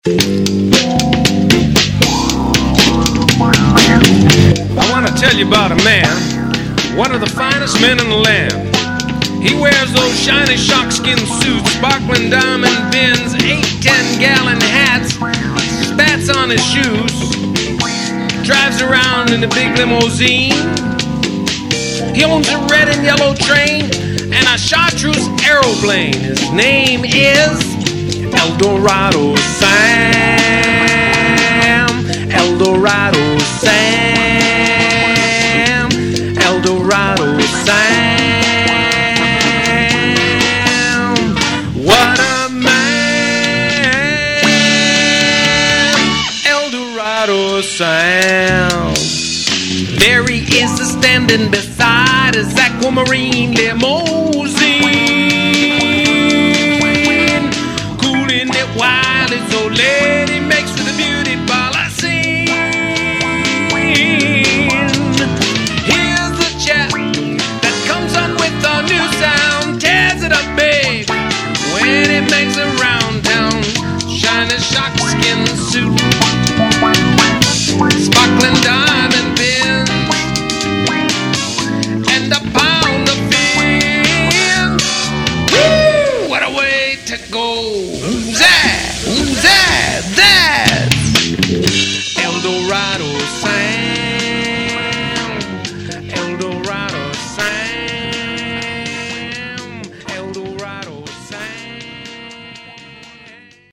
Vocal Band